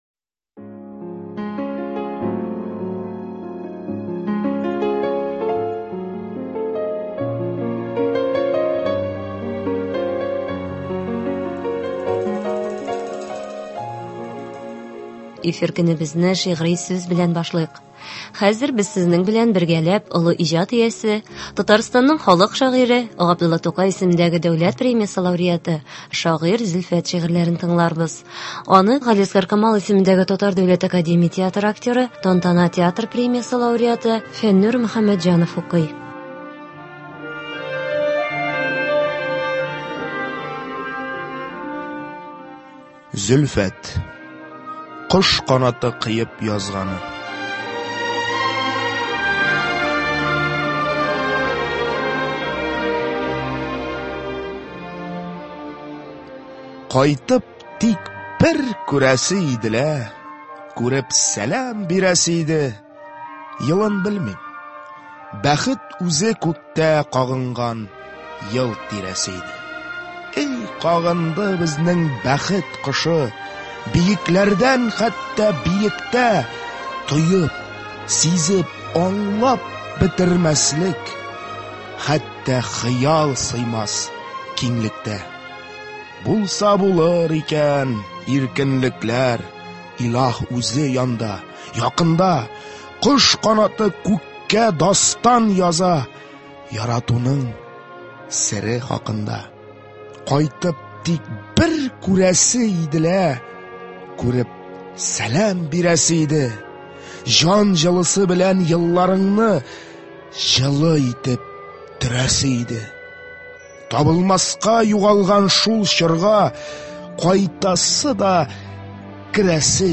Зөлфәт әсәрләреннән әдәби-музыкаль композиция.
Әдәби-музыкаль композиция (27.02.21)